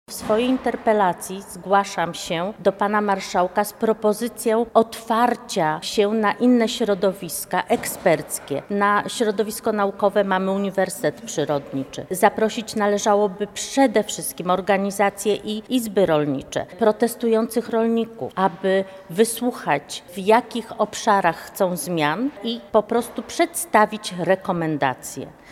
Bożena Lisowska– mówi Bożena Lisowska, radna Sejmiku Województwa Lubelskiego.